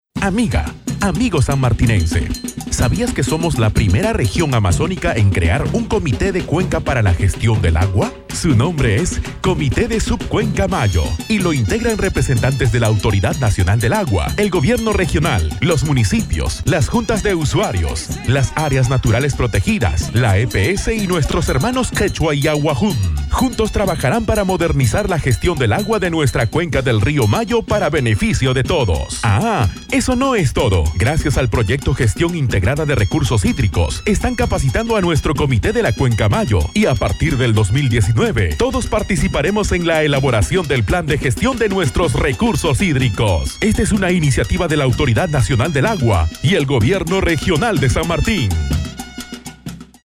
Spot: Comité de Subcuenca Mayo | Mayo